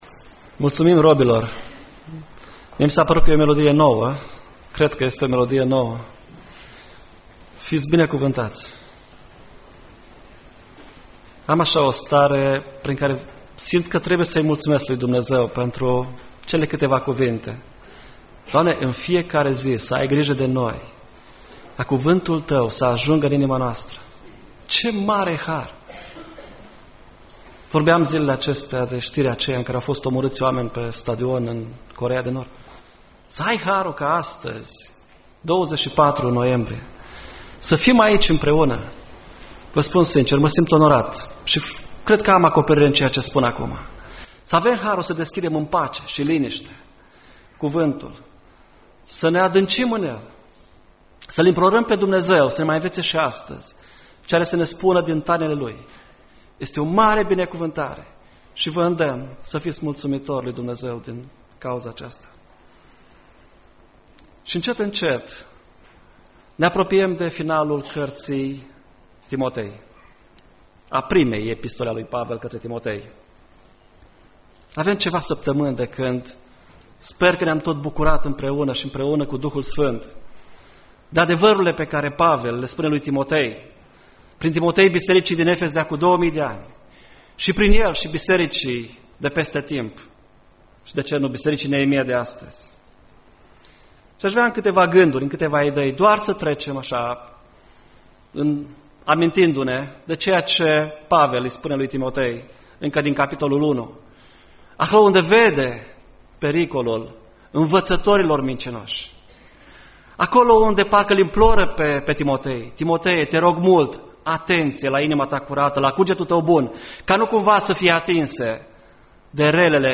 Predica Exegeza 1 Timotei 6 1-2